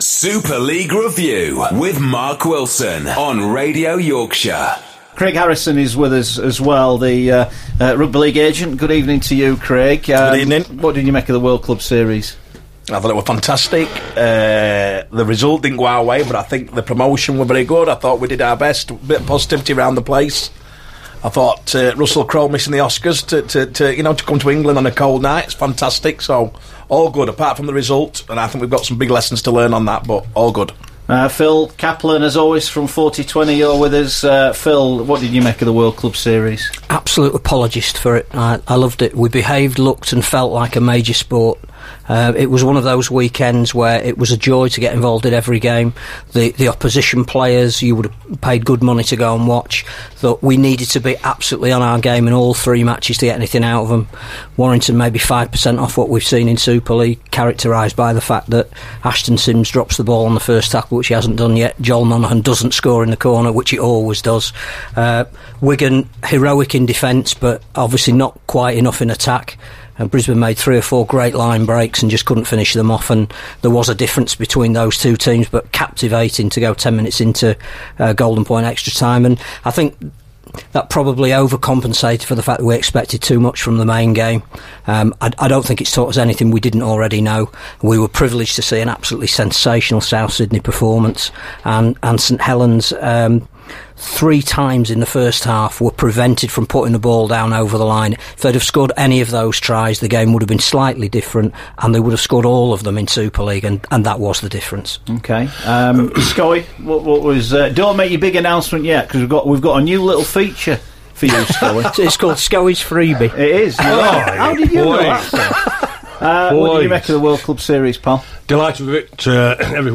We're trying to fit as many people into the Radio Yorkshire studio as possible every Monday night to talk Rugby League